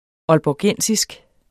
Udtale [ ʌlbɒˈgεnˀsisg ]